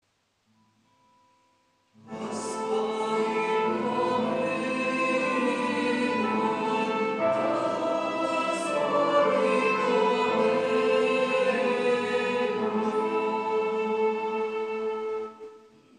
Pregària de Taizé
Parròquia de la M.D. de Montserrat - Diumenge 23 de febrer de 2014